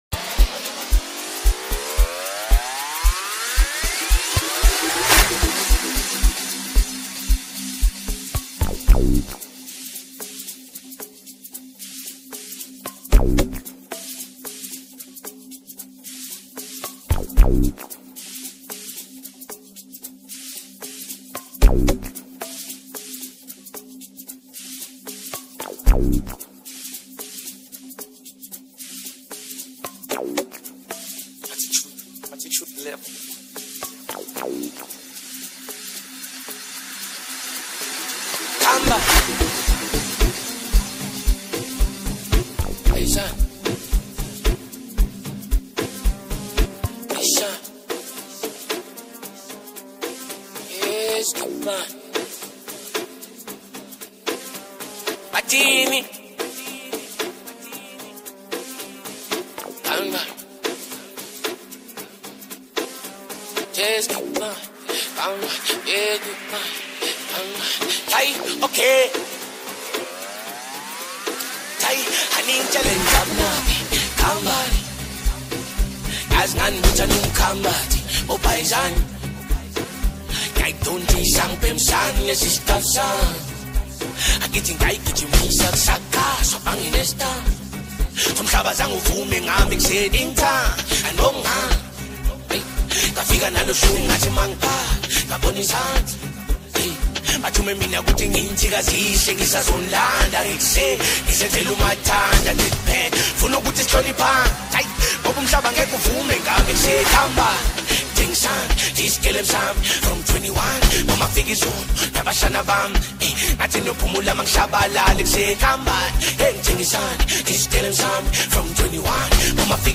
Foreign MusicSouth African
infectious rhythm and energetic vibe